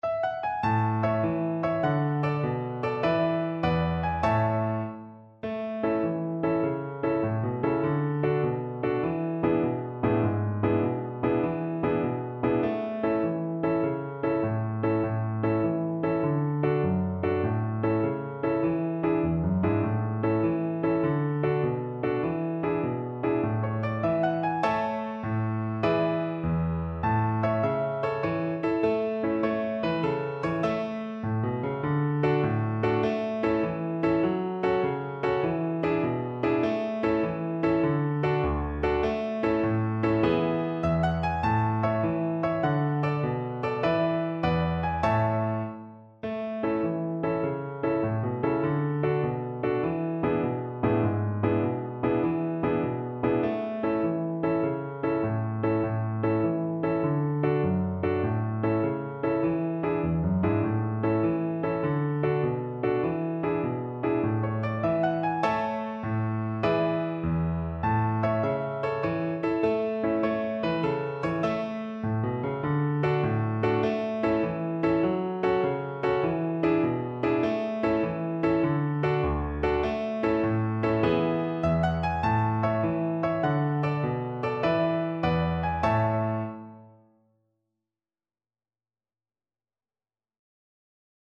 Play (or use space bar on your keyboard) Pause Music Playalong - Piano Accompaniment Playalong Band Accompaniment not yet available transpose reset tempo print settings full screen
A major (Sounding Pitch) (View more A major Music for Violin )
Allegro .=c.100 (View more music marked Allegro)
6/8 (View more 6/8 Music)